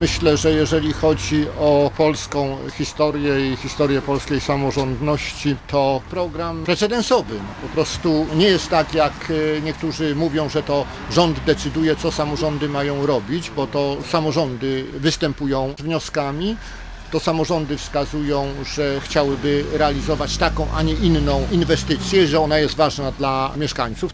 – Lubuskie samorządy otrzymają z Rządowego Funduszu Polski Ład prawie 700 milionów złotych – powiedział poseł Marek Ast, szef lubuskich struktur PiS: